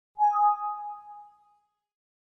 На этой странице собраны классические звуки Windows Vista — системные уведомления, мелодии запуска и завершения работы, а также другие аудиоэлементы ОС.
Windows exclamation mark